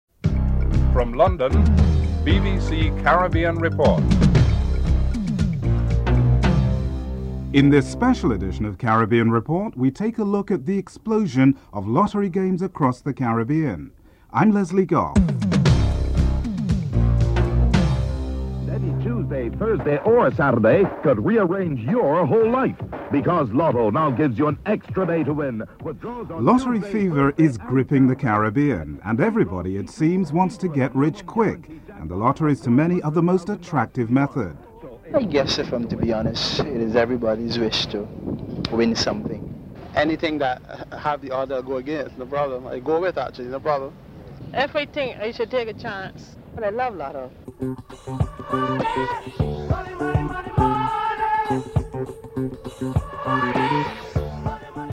Barbadians have their say on their country's ever growing lottery scene.
4. Barbadians talk about the increase in the number of lotteries in their country and Raj Persaud examines why people play the lottery (02:47-04:21)